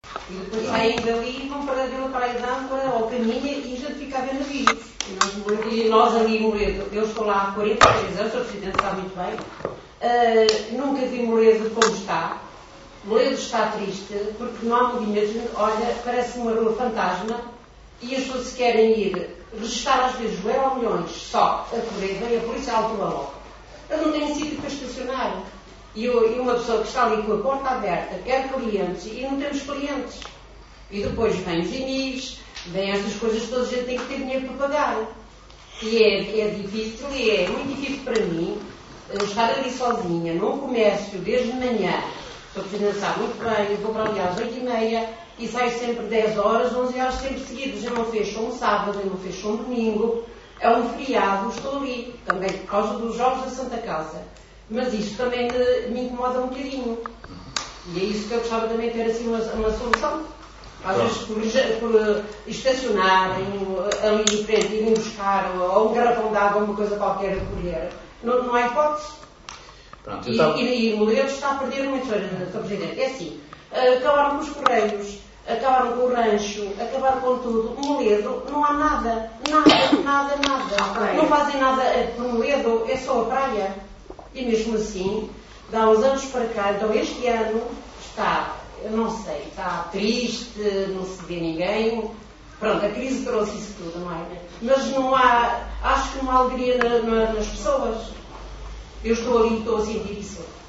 O desabafo de uma comerciante da Av. de Santana.